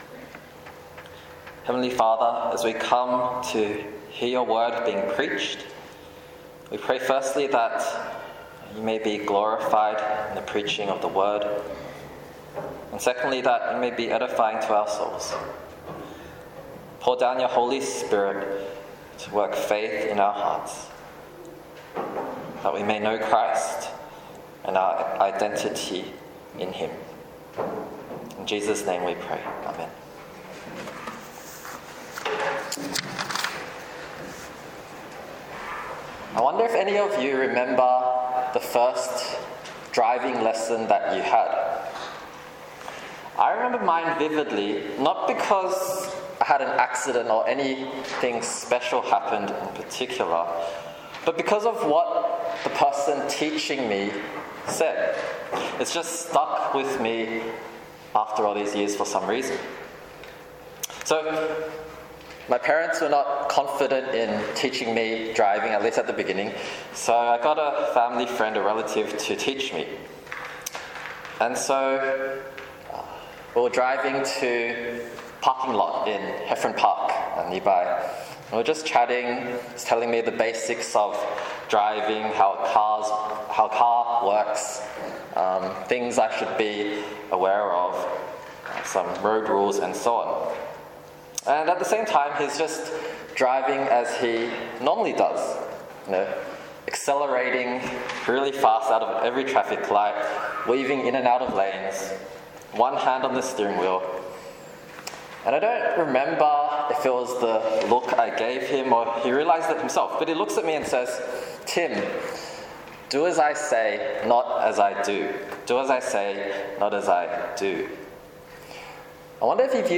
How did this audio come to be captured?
25/06/2023 – Evening Service: The Hypocritical Boaster (Romans 2:17 – 29)